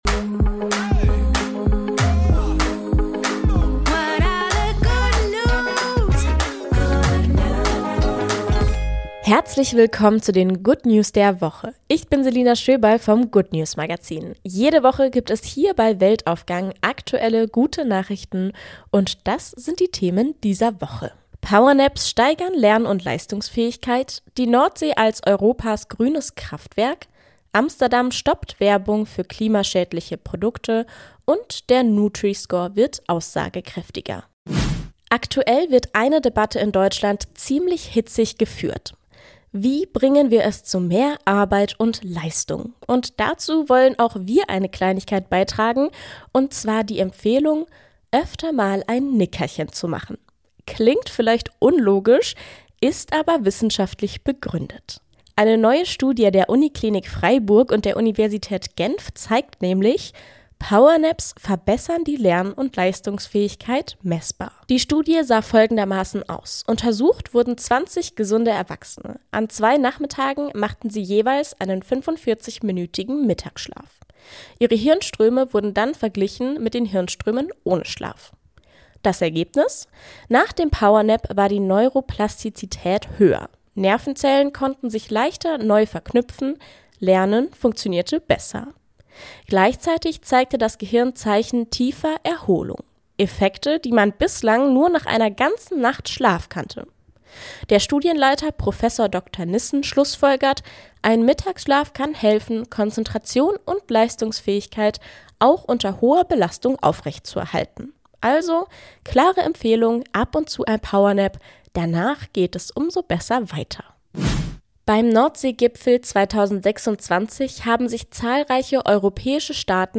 Jede Woche wählen wir aktuelle gute Nachrichten aus und tragen sie